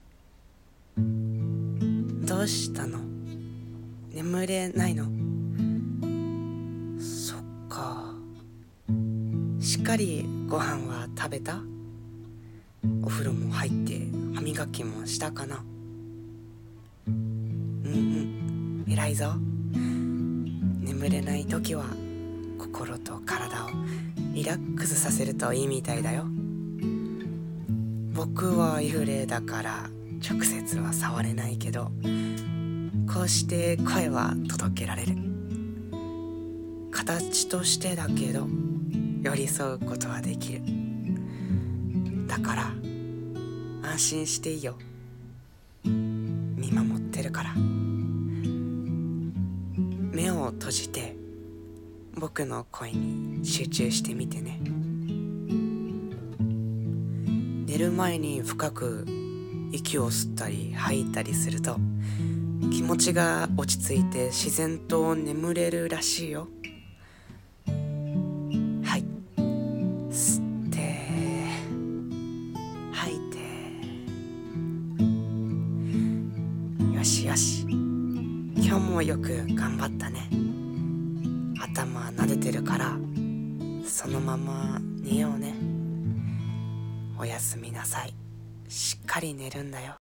朗読台本「寝かしつけてくれる幽霊さん」